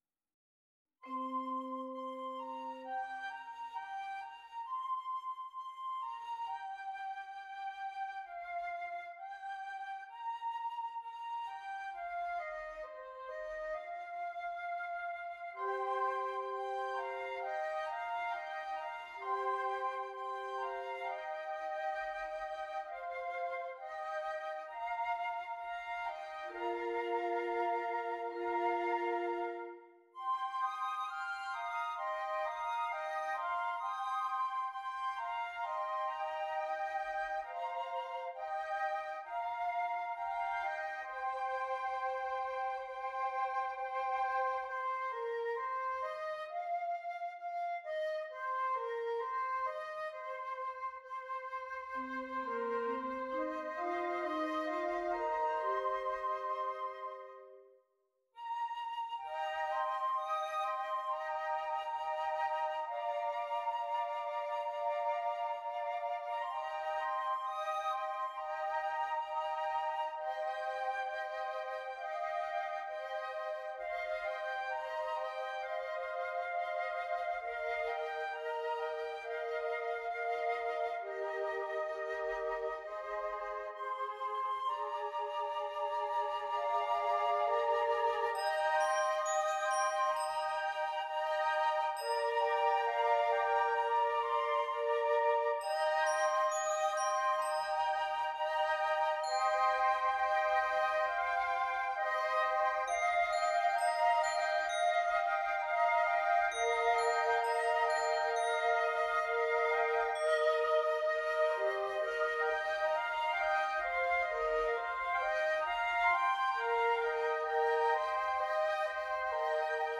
4 Flutes